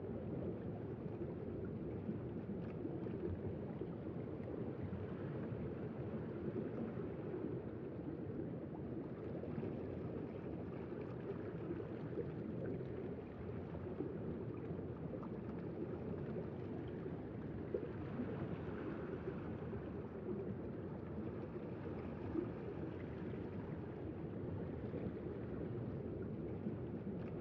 underwater_ambience.ogg